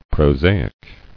[pro·sa·ic]